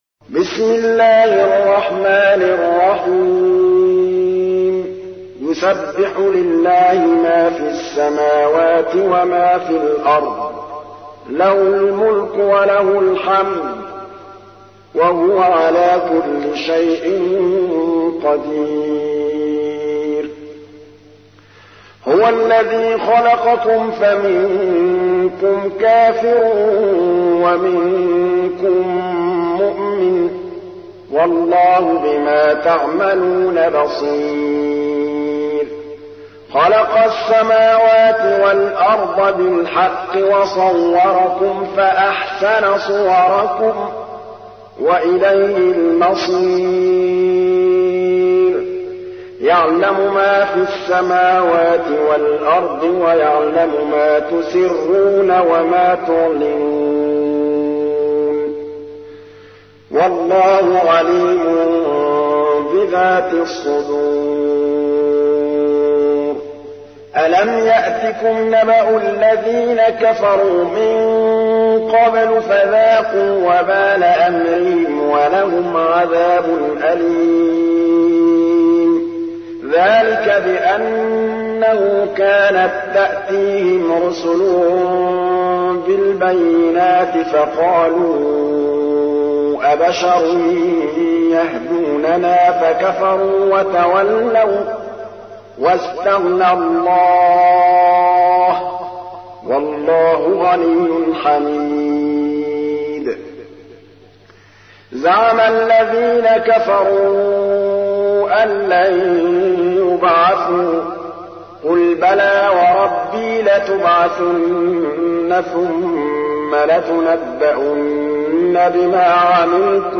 تحميل : 64. سورة التغابن / القارئ محمود الطبلاوي / القرآن الكريم / موقع يا حسين